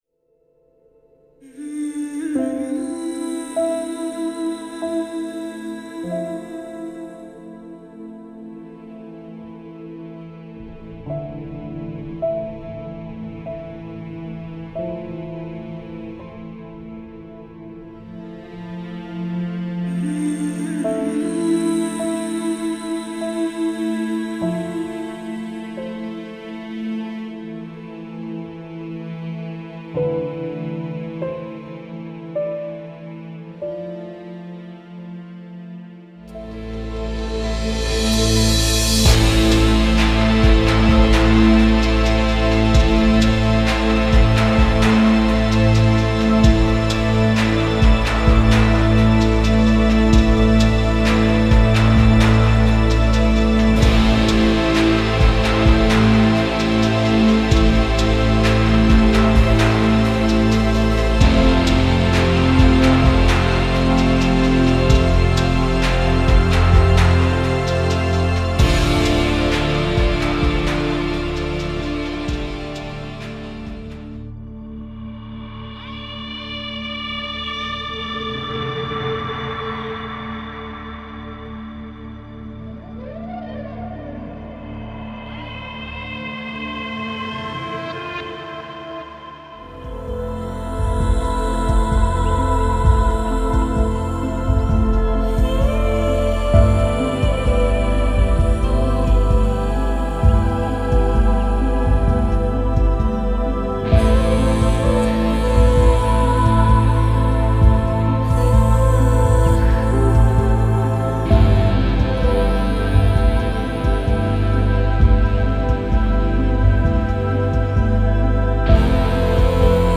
Your label for Electronic Music
file under: Soundtrack, Ambient, NewAge, Progrock, Spacerock